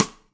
share-stick.wav